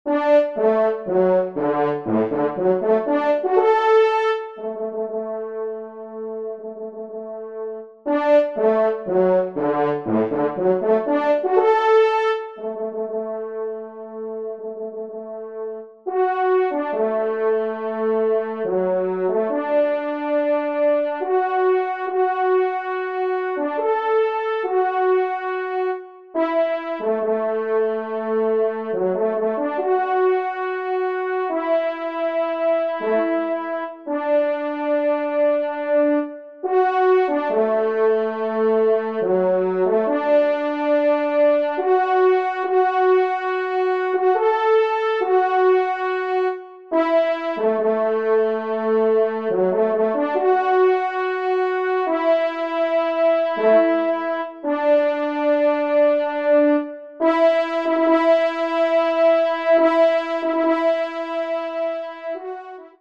1ère Trompe